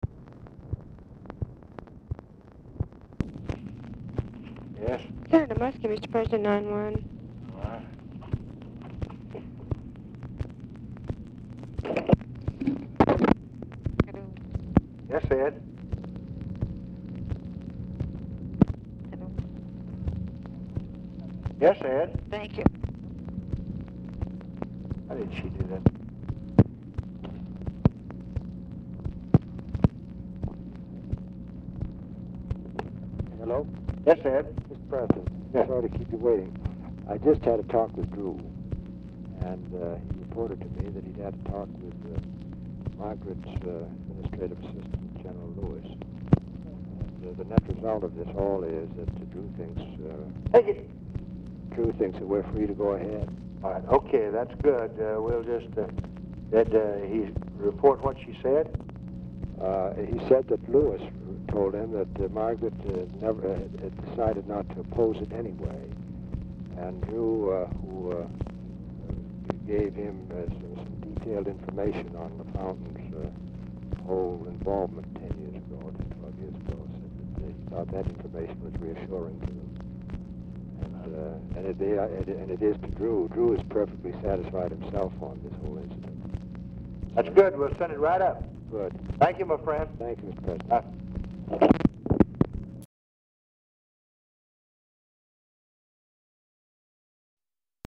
Oval Office or unknown location
MUSKIE ON HOLD 0:33
Telephone conversation
Dictation belt